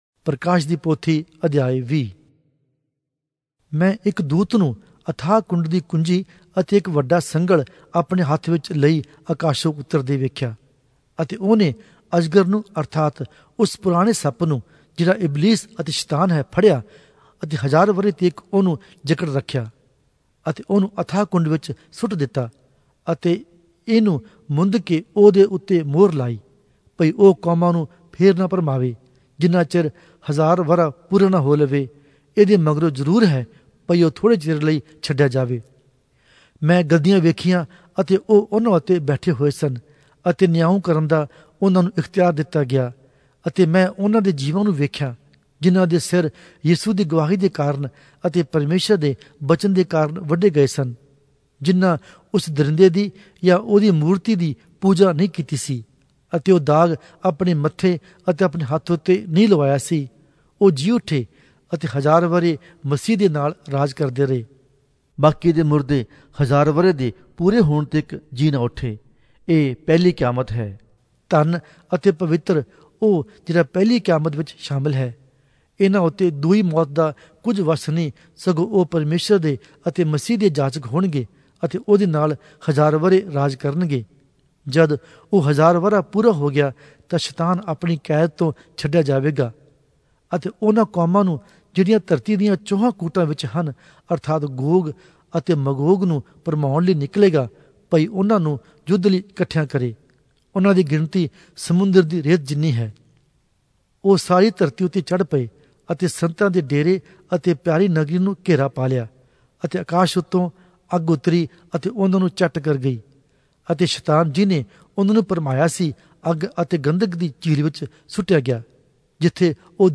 Punjabi Audio Bible - Revelation 6 in Mhb bible version